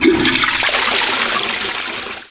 1 channel
toilet.mp3